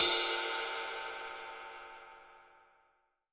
Index of /90_sSampleCDs/AKAI S6000 CD-ROM - Volume 3/Drum_Kit/ROCK_KIT1